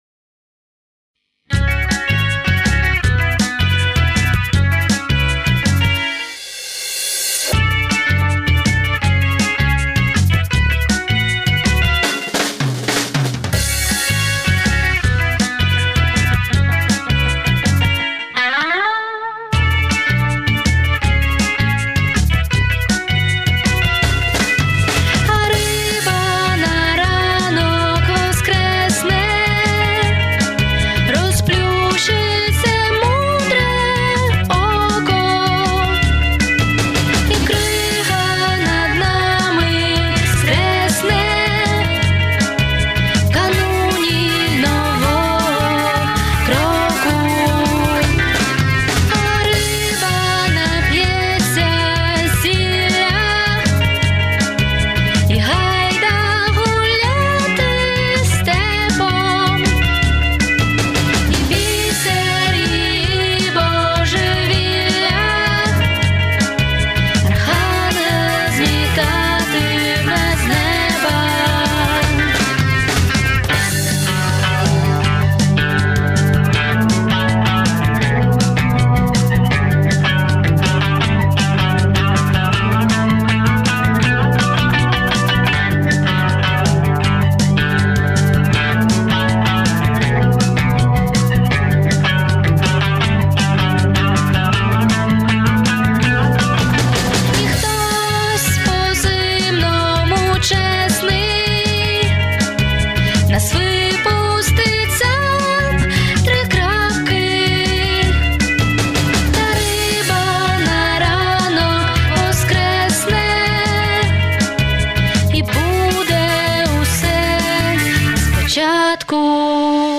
Звичайно, в мене немає мікрофону за 1000 долларів, але той що є, достатньо якісний.